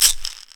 Destroy - ShakerPerc.wav